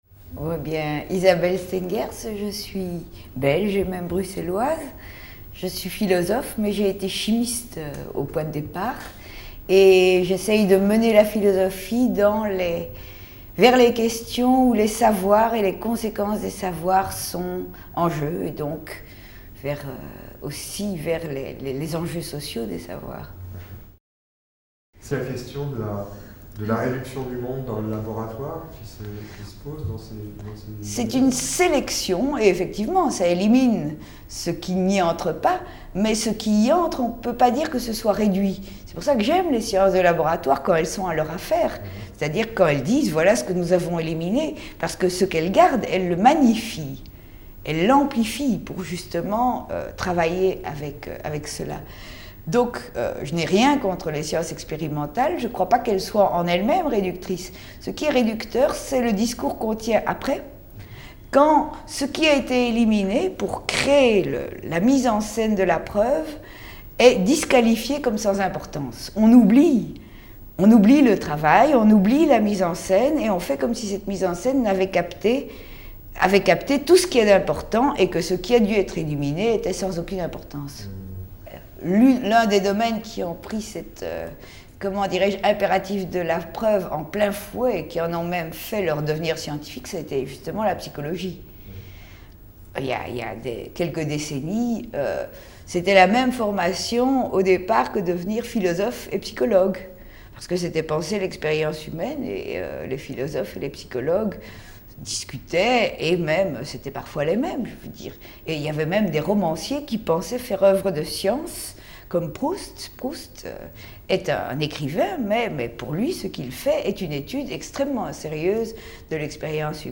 Extrait d’une interview de Isabelle Stengers pour l’installation L’Ombre d’un doute